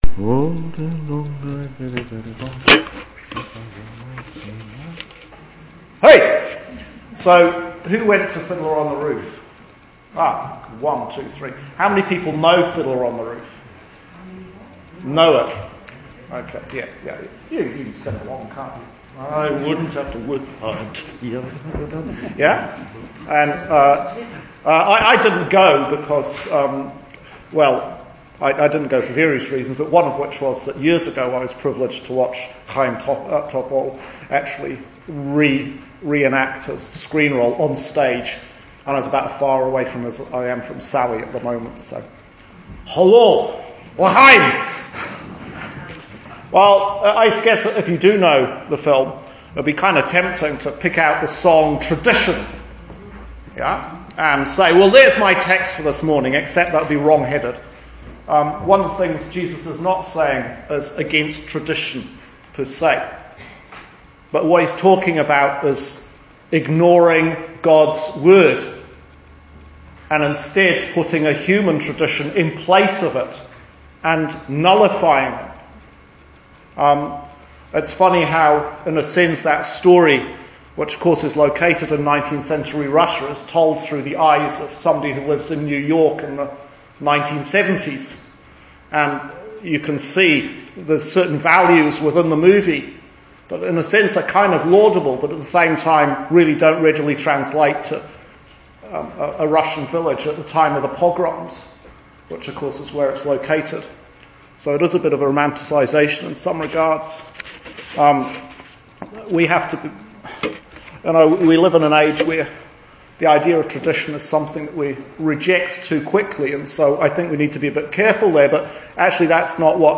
Sermon for the Fourteenth Sunday after Trinity – Year B – 2018